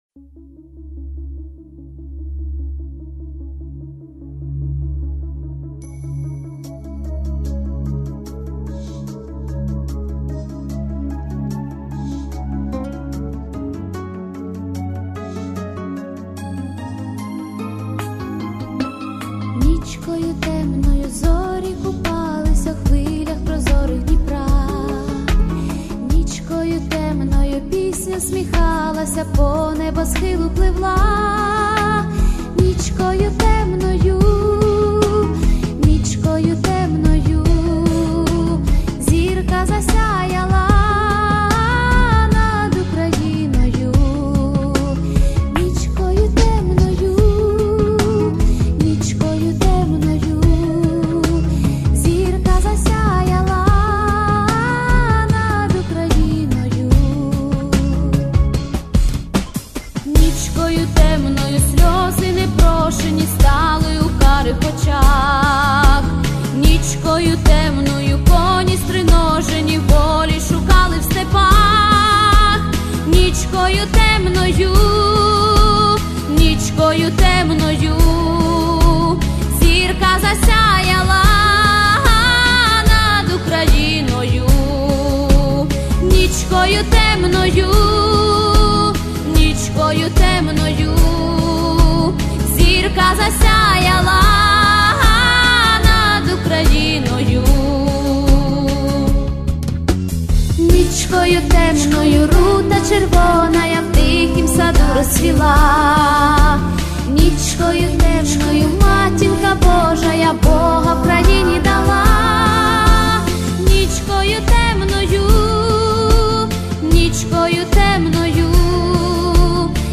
Всі мінусовки жанру Pop-UA
Плюсовий запис